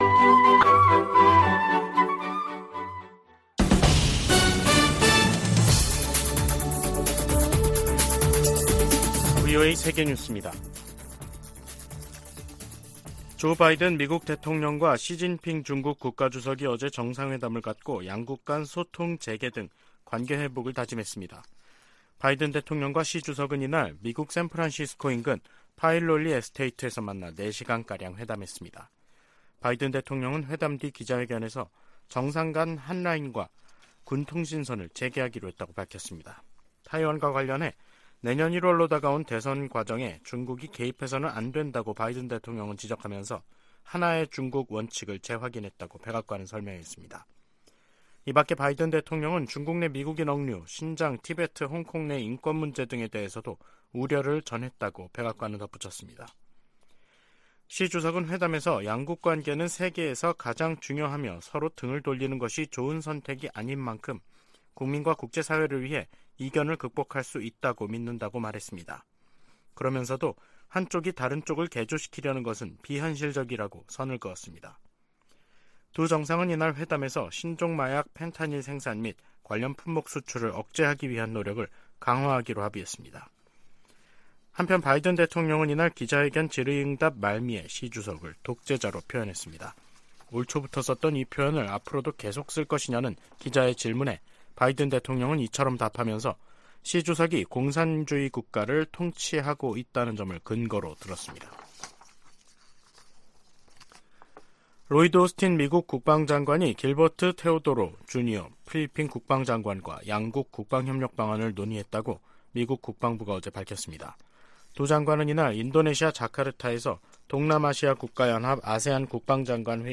VOA 한국어 간판 뉴스 프로그램 '뉴스 투데이', 2023년 11월 16일 3부 방송입니다. 조 바이든 미국 대통령이 15일 시진핑 중국 국가 주석과의 회담에서 한반도의 완전한 비핵화에 대한 미국의 의지를 다시 한번 강조했습니다. 유엔총회 제3위원회가 북한의 인권 유린을 규탄하는 북한인권결의안을 19년 연속 채택했습니다. 북한과 러시아는 정상회담 후속 조치로 경제공동위원회를 열고 무역과 과학기술 등 협력 방안에 합의했습니다.